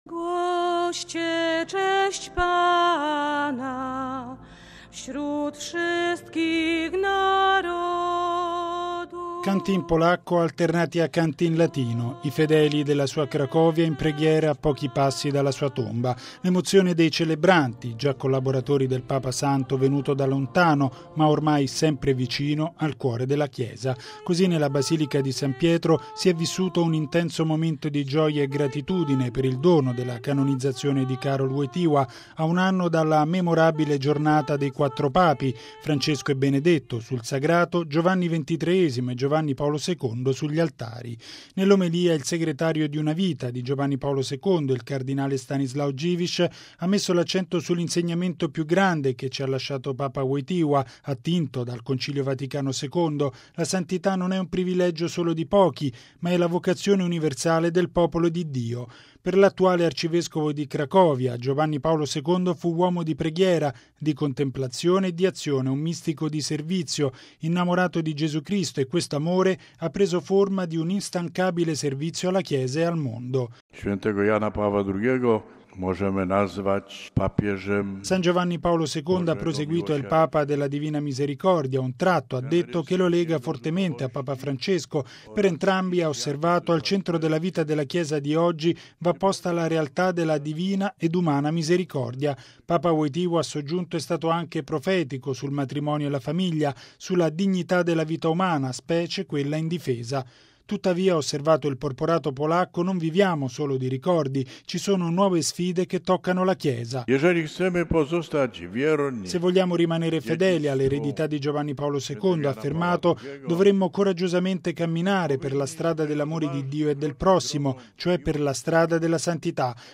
Canti in polacco alternati a canti in latino, i fedeli della “sua” Cracovia in preghiera a pochi passi dalla sua tomba.